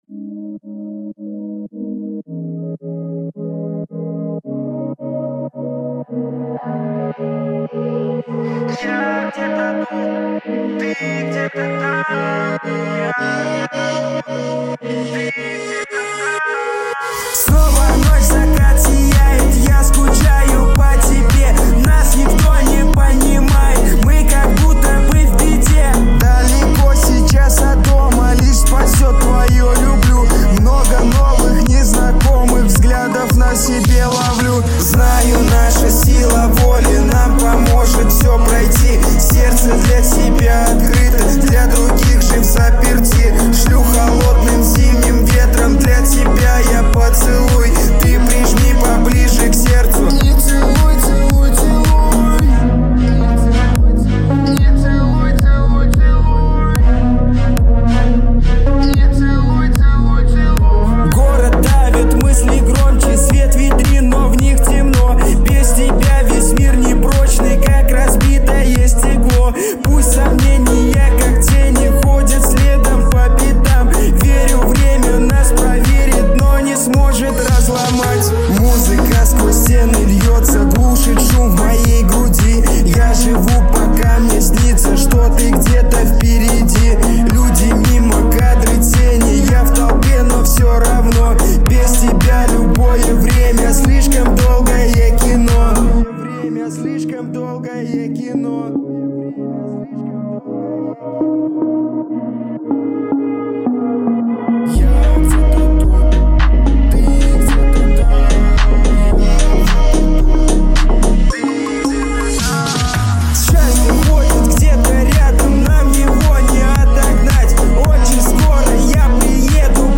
Трек размещён в разделе Рэп и хип-хоп / Русские песни / Поп.